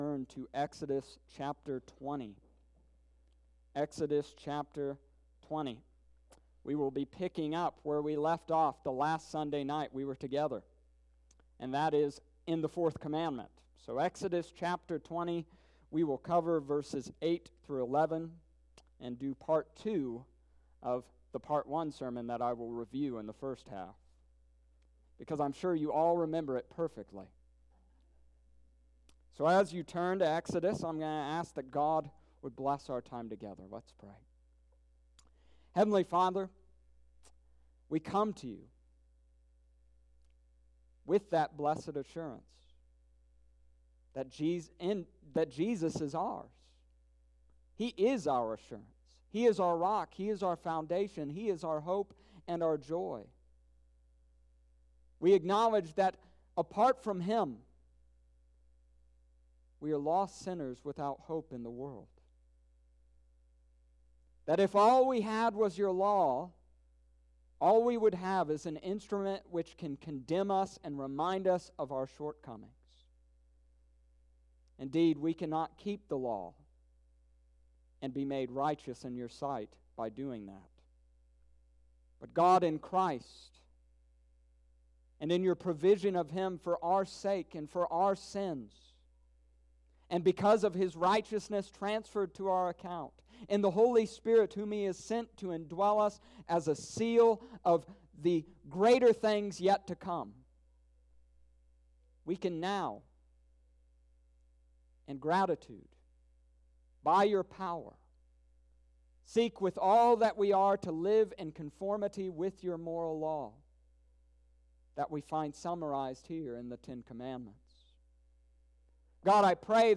Exodus Chapter 20 verses 8-11 Sunday evening service May 10th,2020